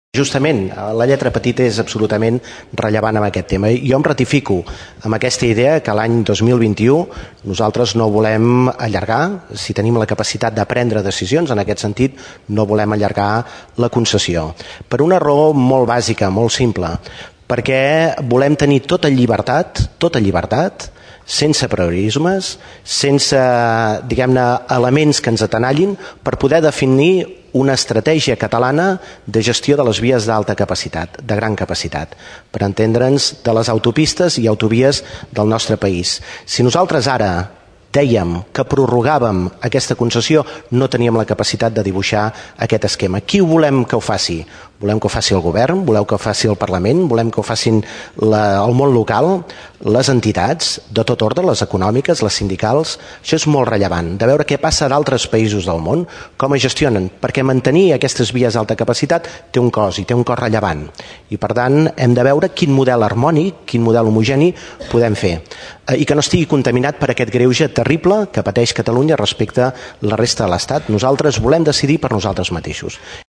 A pregunta del diputat Jordi Terrades i Santacreu del grup del PSC a la sessió de control del Parlament sobre l’autopista C-32 al seu pas pel Maresme, el conseller de Territori i Sostenibilitat Josep Rull respon que es mantenen ferms en la seva decisió de no allargar-ne la concessió més enllà del 2021. Rull afegeix que la generalitat buscarà les alternatives econòmiques per fer viable el manteniment de la infraestructura.